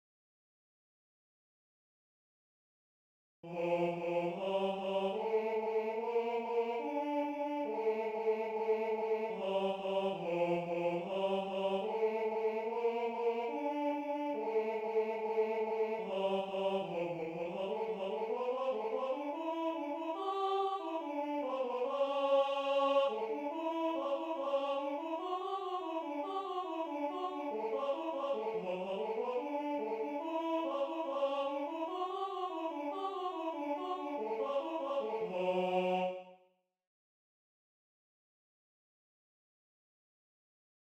Gånglåt från Rättvik alt sjungs ej